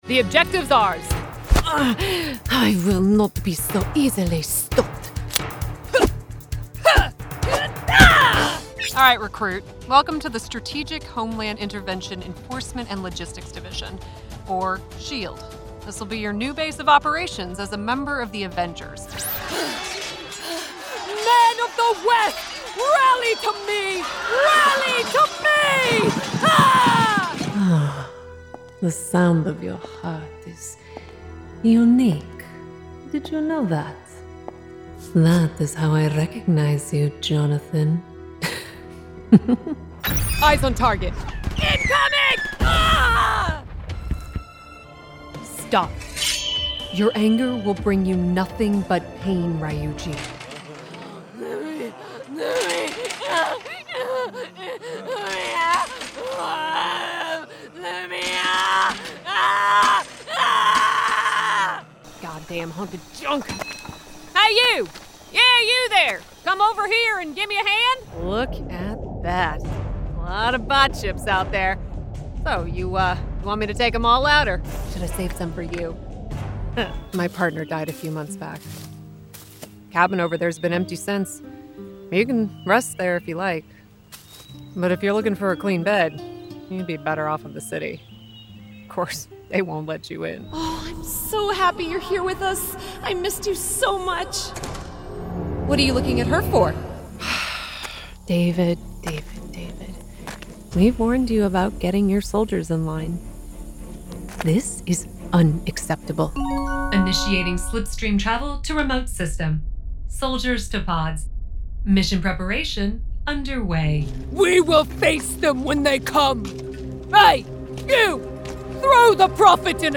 Video Game Demo